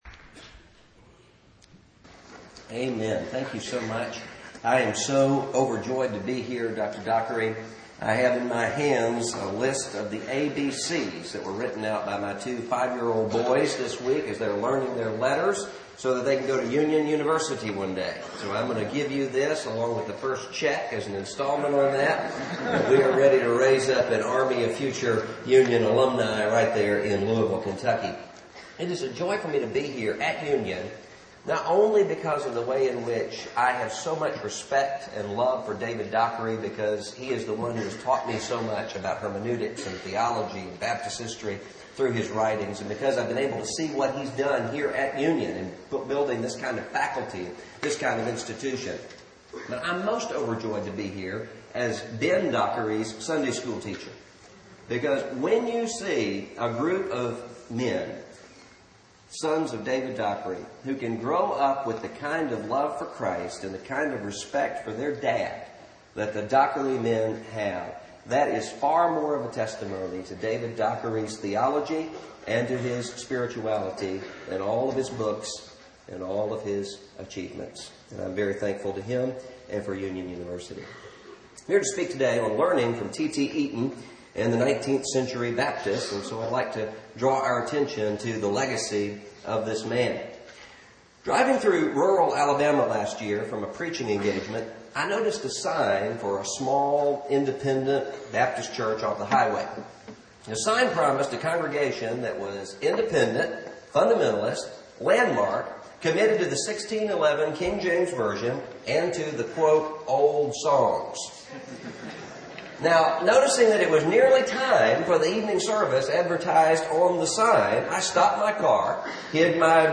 Baptist Identity & Founders Day Chapel: Russell Moore